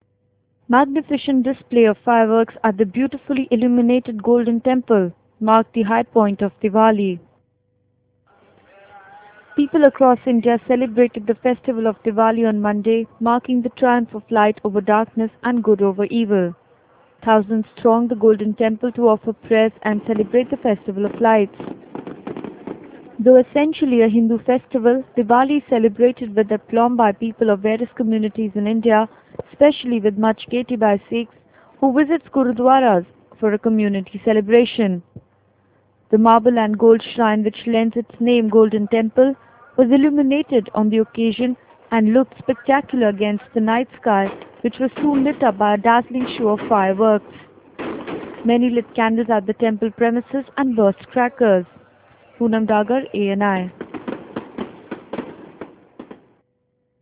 Display of fireworks at the Golden Temple on the night of Divali.